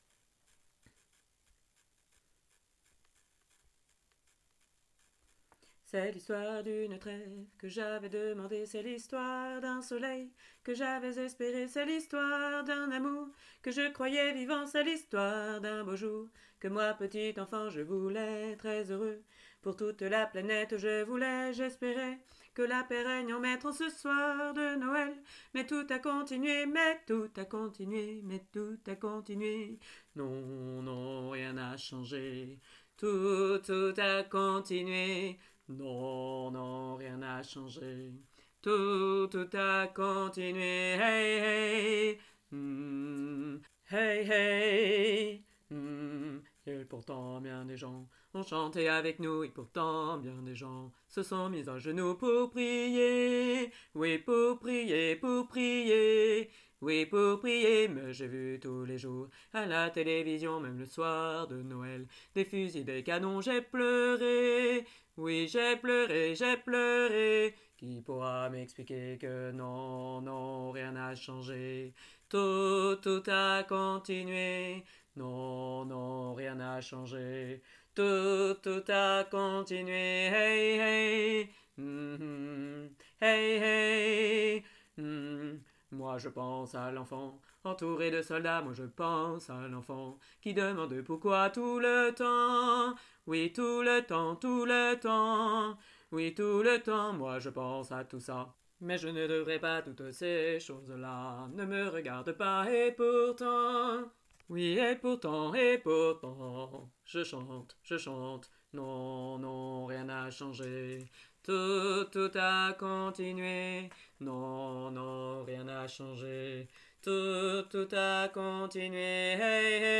hommes.mp3